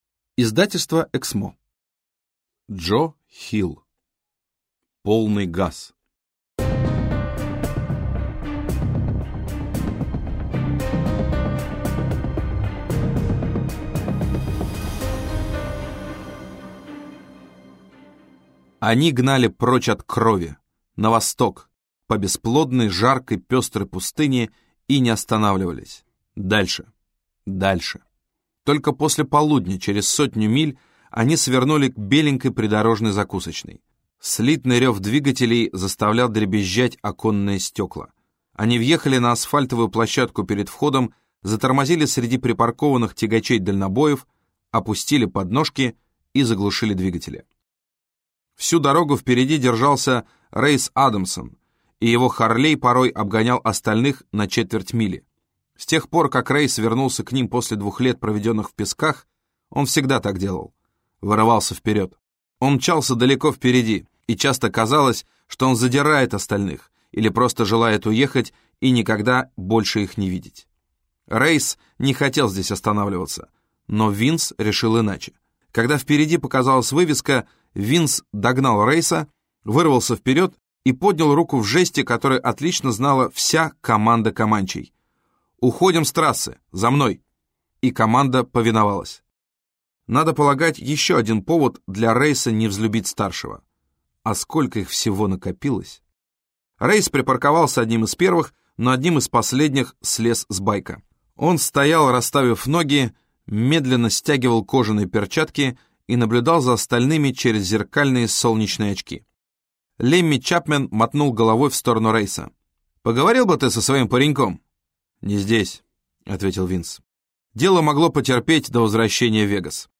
Аудиокнига Полный газ - купить, скачать и слушать онлайн | КнигоПоиск